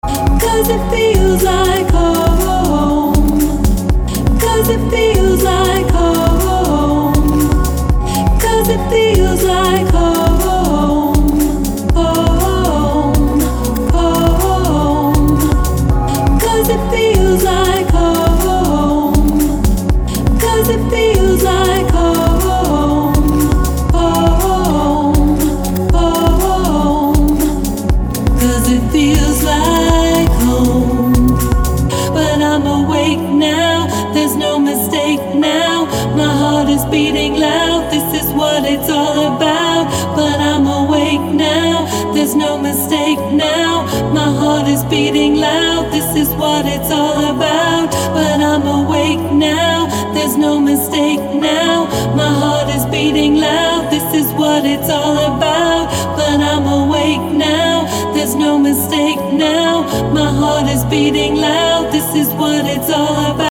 • Качество: 256, Stereo
женский вокал
deep house
грустные
dance
спокойные
медленные
красивый женский голос